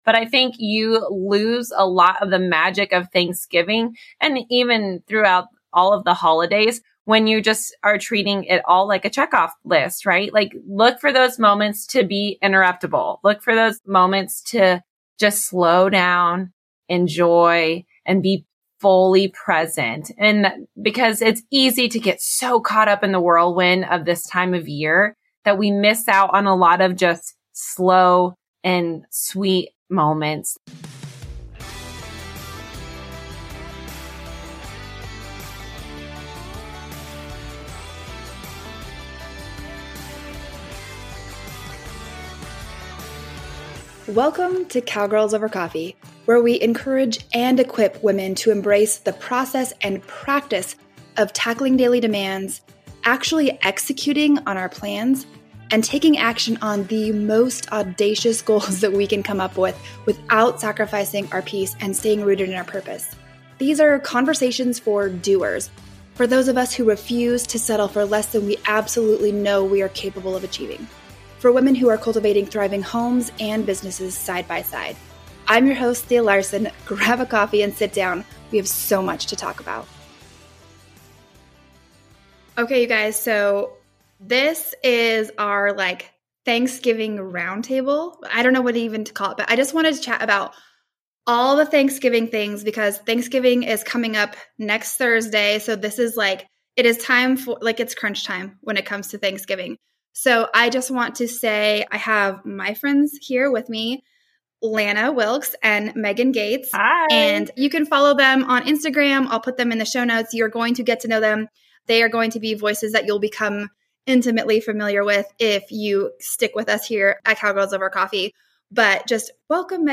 The conversation touches on a wide range of aspects of the holiday from the importance of planning, asking for help when needed, and focusing on gratitude and mental well-being. Tune in for this round-table conversation with friends sharing our unique approaches and tips to savor the holidays!